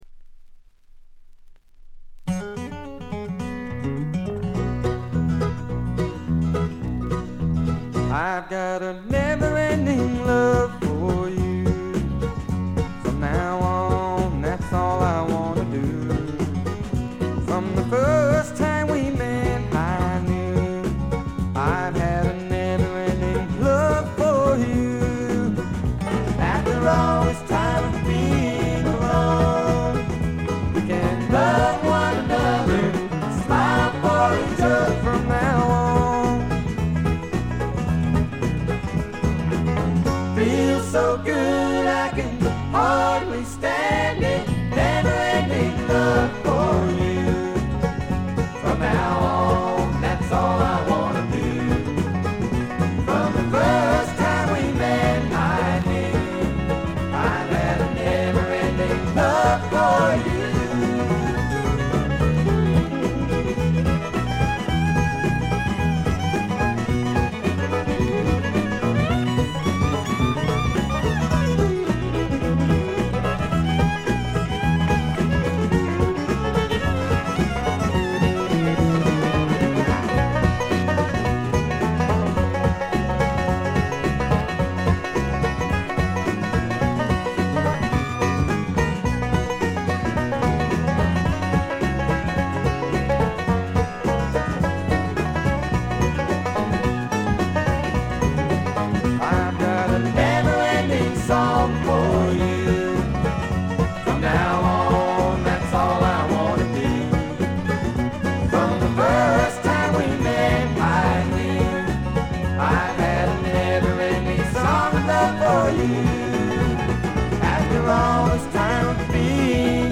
ほとんどノイズ感無し。
試聴曲は現品からの取り込み音源です。
Electric & Acoustic Guitars, Lead Vocals
Guitar & Vocals
Fiddle
Electric Bass
Acoustic Bass
Drums
Mandolin & Vocals